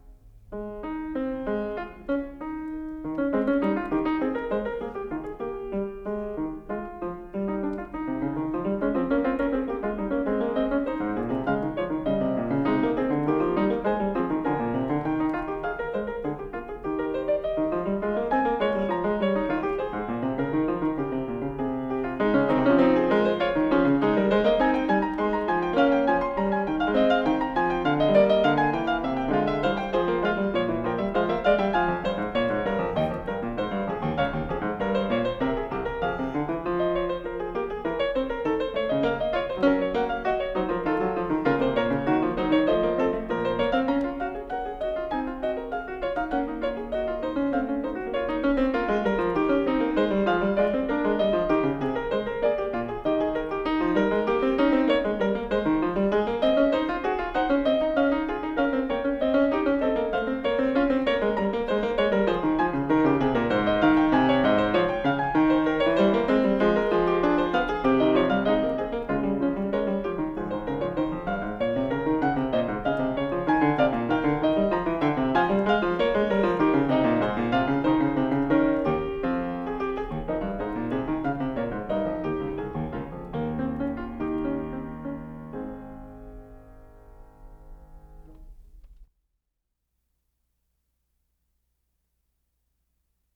02 Wtc Vol I, Fugue No 17 In A Flat Major, Bwv862